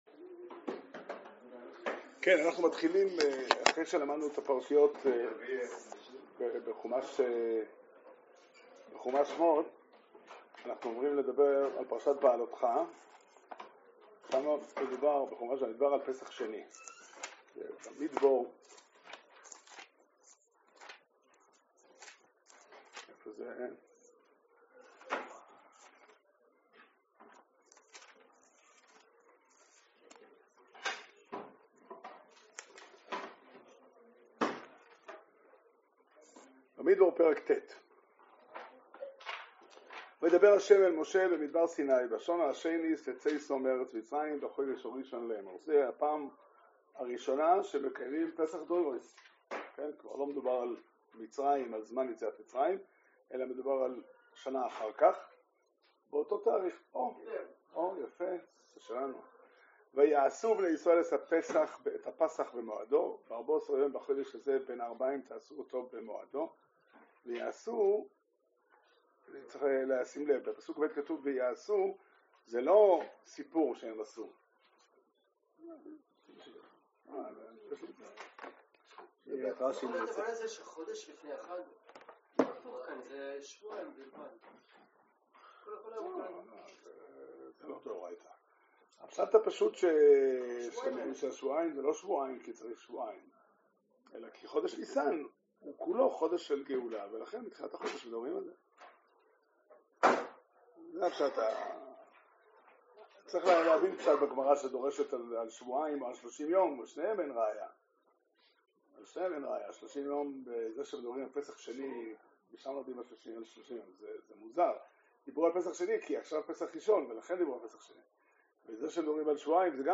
שיעור שנמסר בבית המדרש פתחי עולם בתאריך כ״ז באדר תשפ״ג